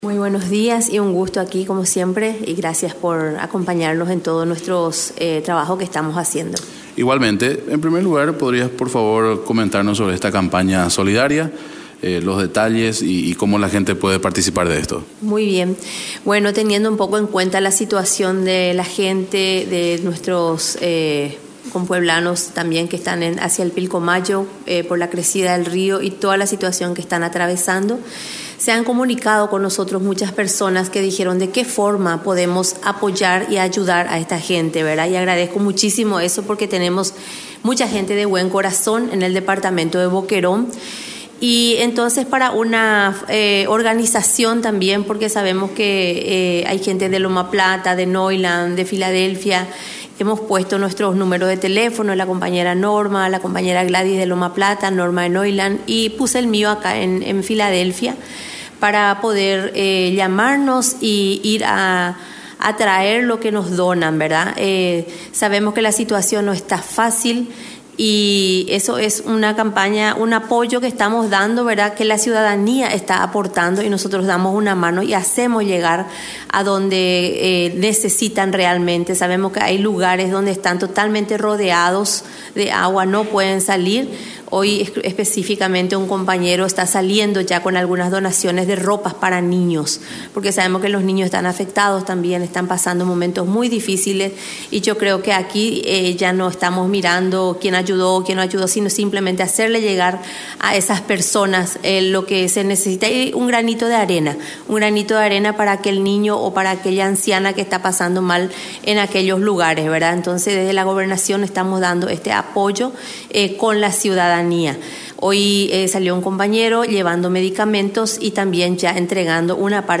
Entrevistas / Matinal 610
Campaña solidaria para los pobladores de Pilcomayo respecto a este y otro tema conversamos con Sonia Samudio (Secretaria de la niñez, mujer y adolescencia).
Entrevistado: Sonia Samudio
Estudio Central, Filadelfia, Dep. Boquerón